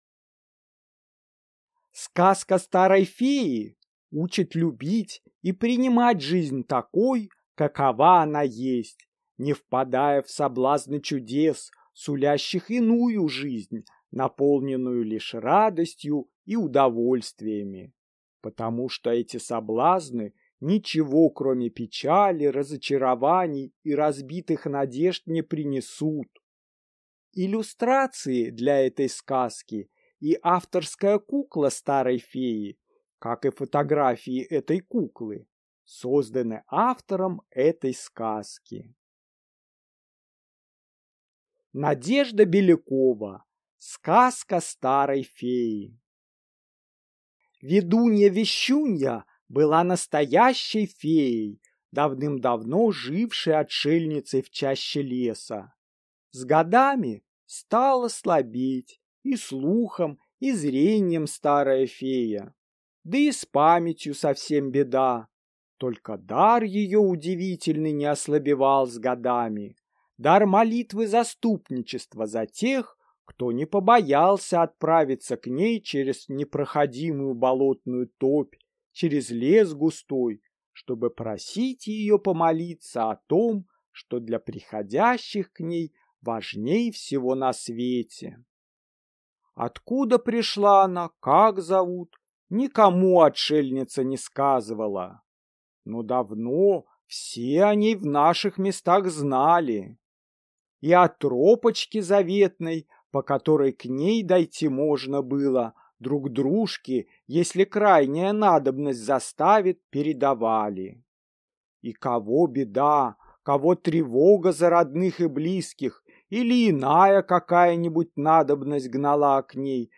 Аудиокнига Сказка старой феи | Библиотека аудиокниг